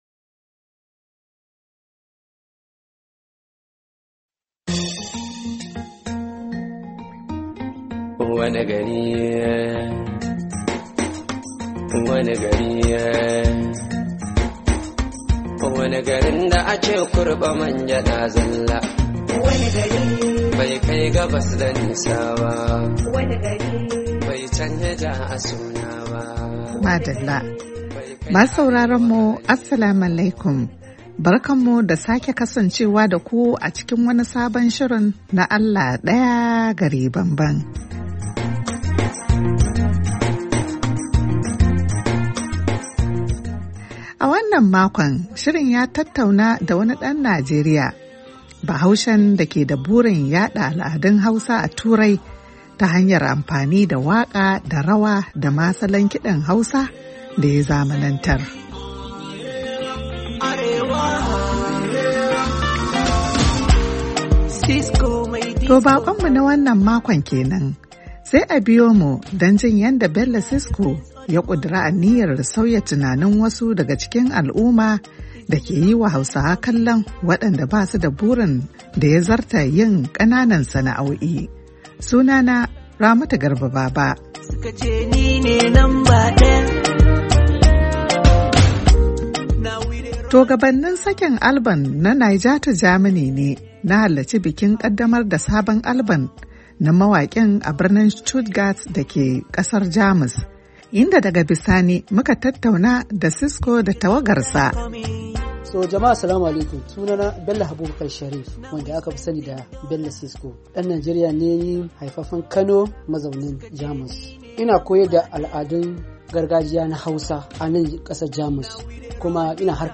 BONN, GERMANY - A shirin na wannan makon mun tattauna ne da wani ‘dan Najeriya, Bahaushe da ke da burin yada al’adun Hausa a Turai ta hanyar amfani da waka, rawa da kuma irin kidan Hausa.